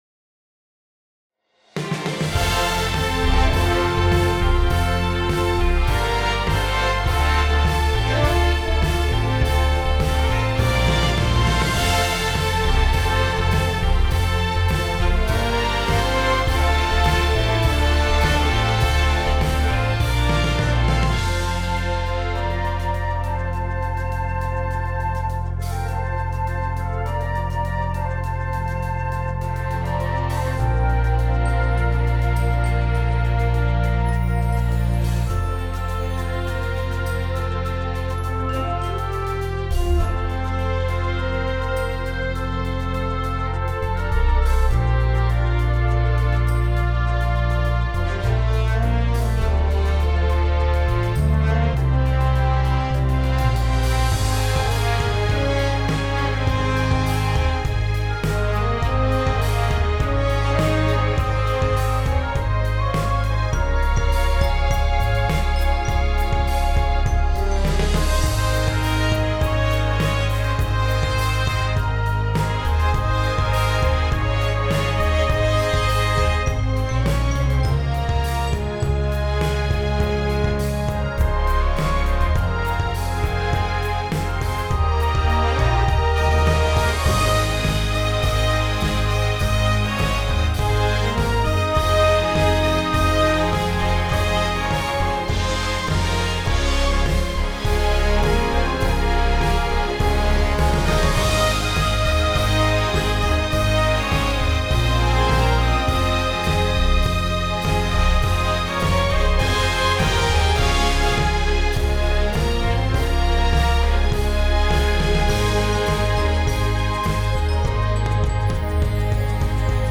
This energetic favorite
an exciting service opener!